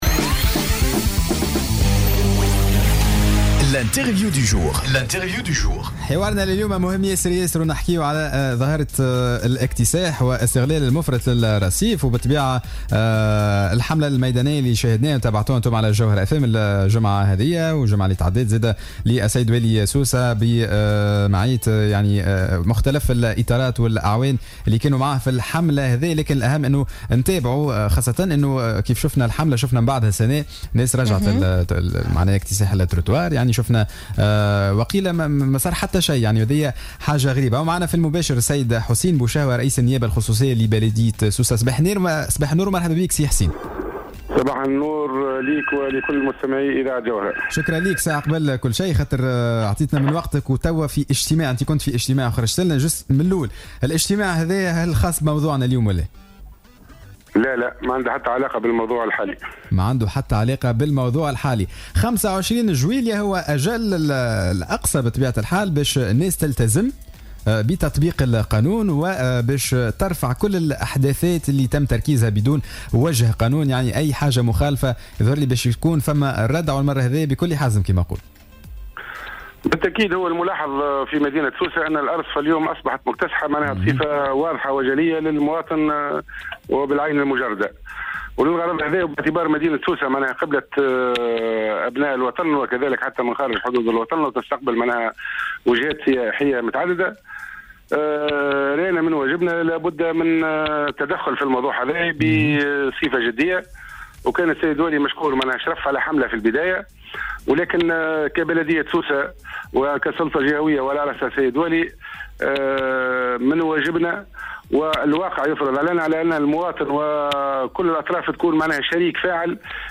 أكد رئيس النيابة الخصوصية لبلدية سوسة حسين بوشهوة في مداخلة له على الجوهرة "اف ام" اليوم الثلاثاء 18 جويلية 2017 أن الحملة على مكتسحي الأرصفة متواصلة داعيا كل المعنيين بضرورة الاتصال بالبلدية لتسوية وضعياتهم في أقرب الاجال.